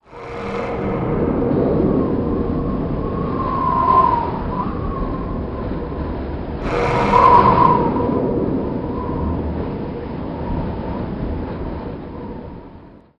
tornadospawnsound.wav